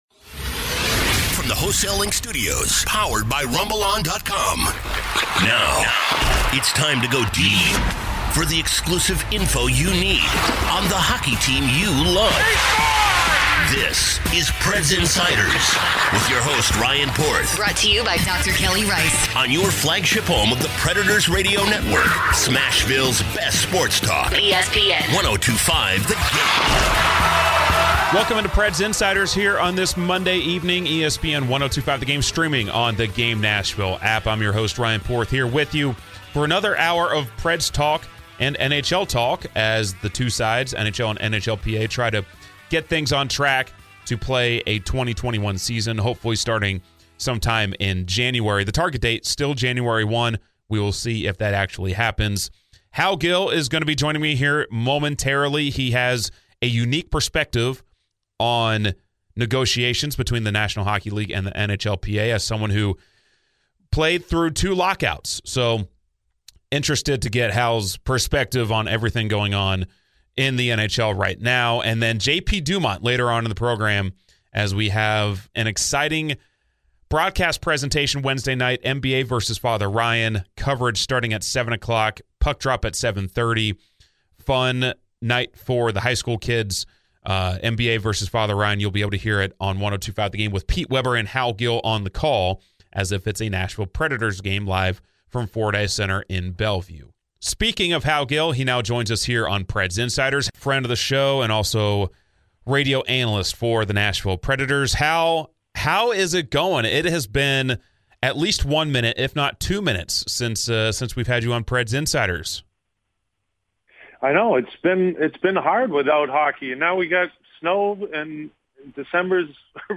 interviews a pair of former Preds on this week's edition of Preds Insiders!